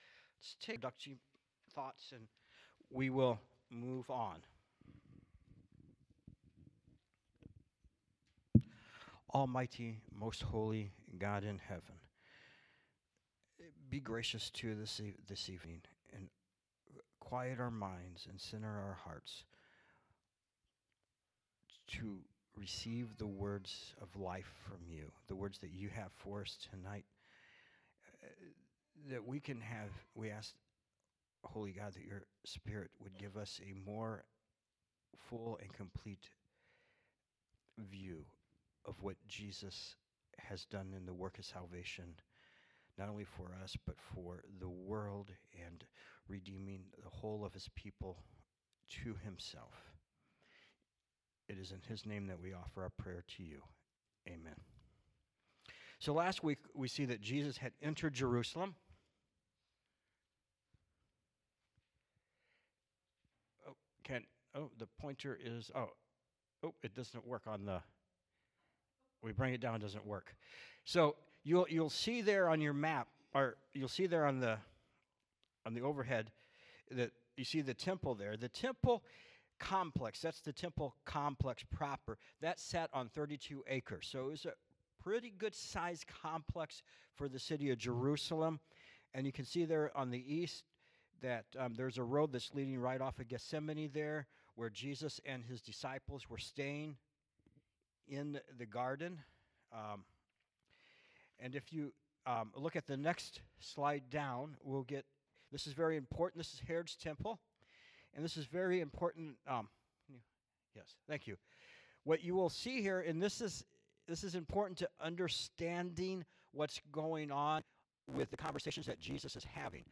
3-8-20-PM-Sermon.mp3